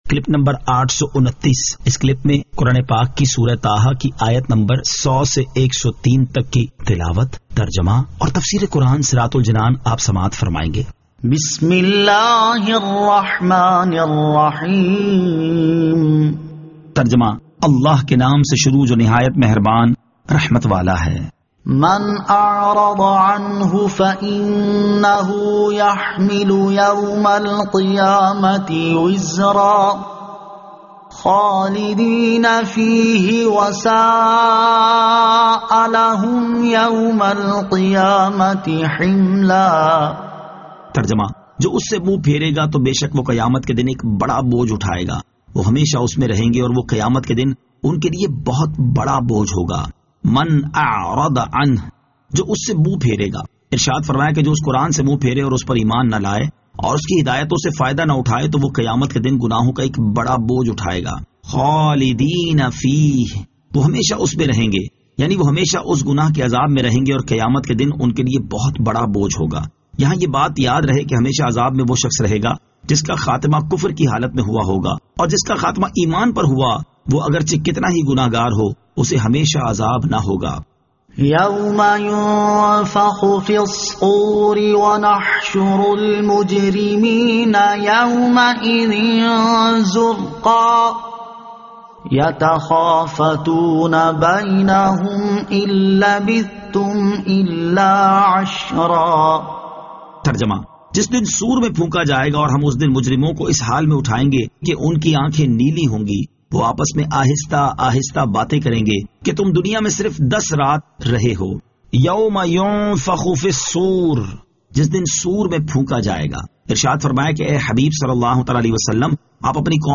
Surah Taha Ayat 100 To 103 Tilawat , Tarjama , Tafseer